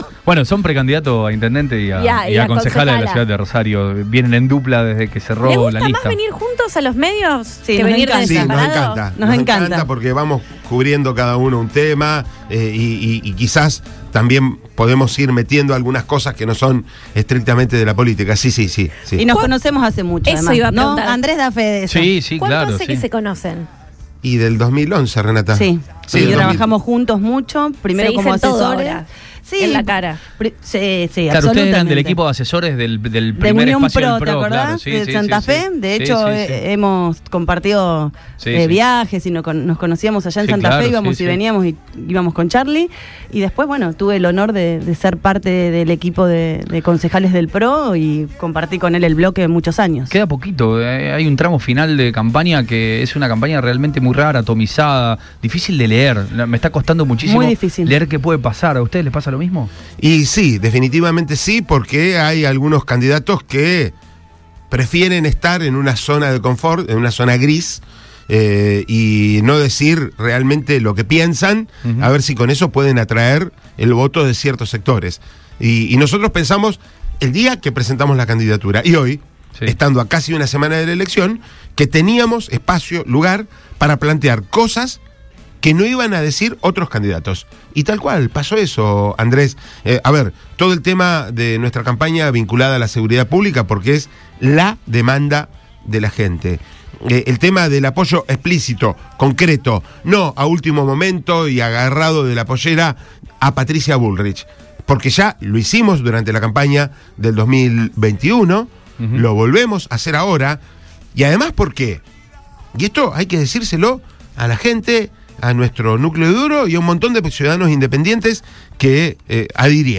pasaron por los estudios de Radio Boing para dialogar con el equipo de Lo Mejor de Todo. Ambos representantes de Unidos para Cambiar Santa Fe coincidieron en la problemática de la ciudad y aseguraron que Rosario tiene que ser autónoma.